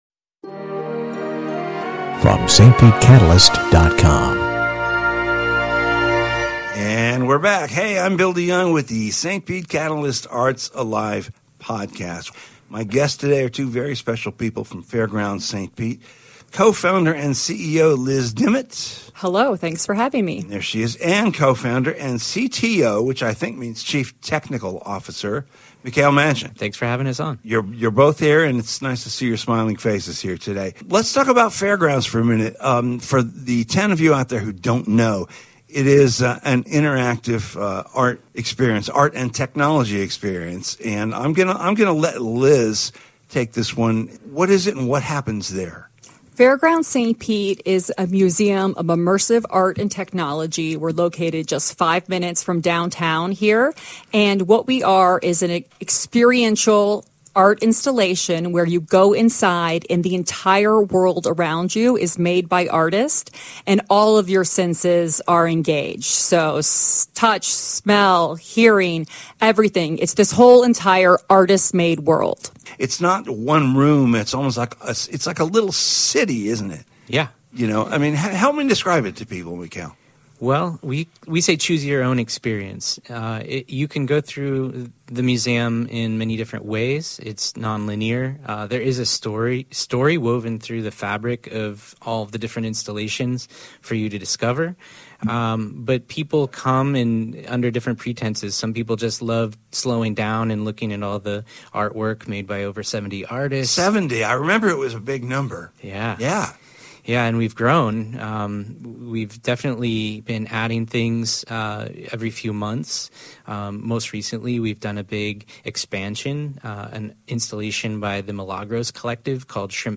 During the conversation